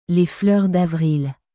Les fleurs d’avrilレ フルール ダヴリル